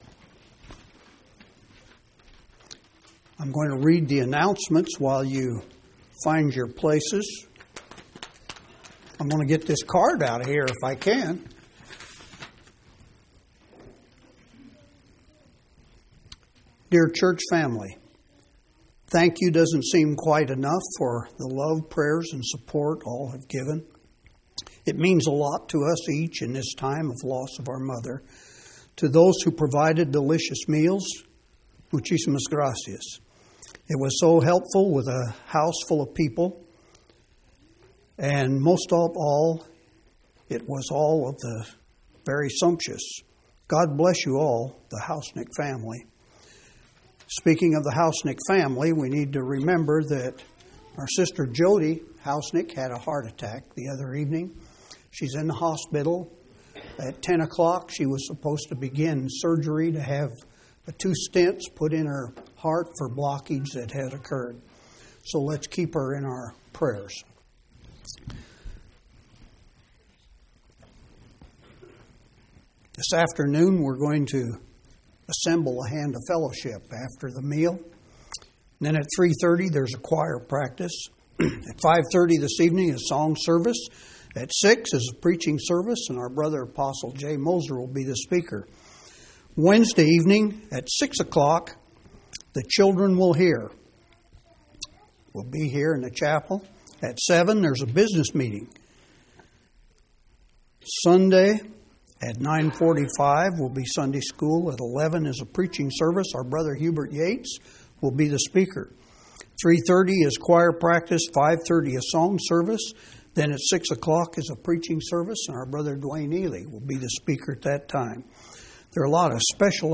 1/12/2003 Location: Phoenix Local Event